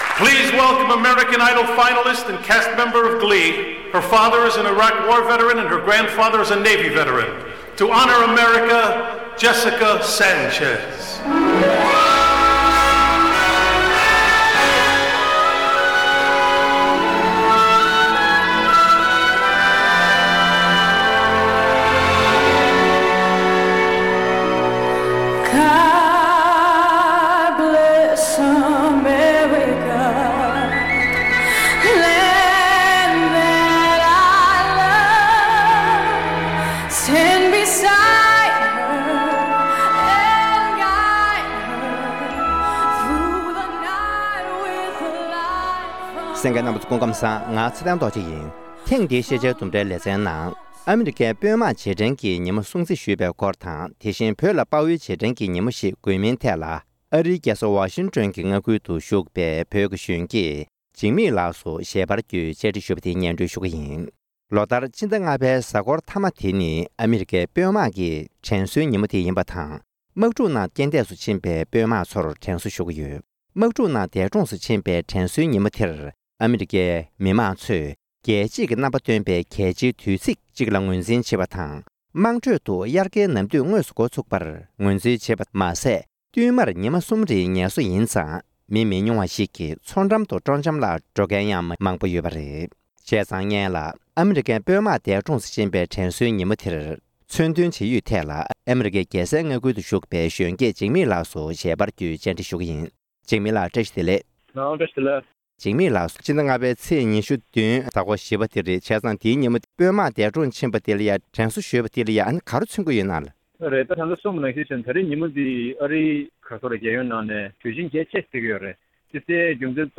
༄༅༎ཐེངས་འདིའི་ཤེས་བྱའི་ལྡུམ་ར་ཞེས་པའི་ལེ་ཚན་ནང་དུ། ཟླ་འདིའི་ཕྱི་ཚེས་ ༢༧ཉིན་ཨ་མི་རི་ཀའི་དཔོན་དམག་འདས་གྲོངས་སུ་ཕྱིན་པ་རྣམས་ལ་རྗེས་དྲན་གྱི་ཉིན་མོ་སྲུང་བརྩི་ཞུས་པའི་སྐོར་དང༌། དེ་བཞིན་བོད་ལ་དཔའ་བོའི་རྗེས་དྲན་གྱི་ཉིན་མོ་ཞིག་དགོས་མིན་ཐད། ཨ་རིའི་རྒྱལ་ས་ལྦ་ཤིང་ཊོན་ཁུལ་དུ་བཞུགས་པའི་བོད་པའི་ན་གཞོན་ཤེས་ཡོན་ཅན་ཞིག་ལ་ཞལ་པར་རྒྱུད་བཅར་འདྲི་ཞུས་པར་གསན་རོགས་ཞུ༎